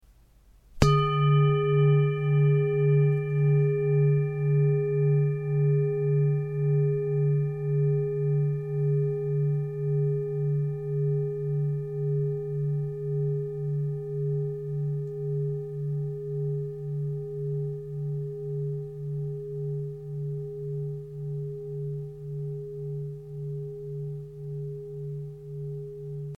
Tibetische Klangschale - MARS + URANUS
Durchmesser: 20,4 cm
Grundton: 145,40 Hz
1. Oberton: 414,55 Hz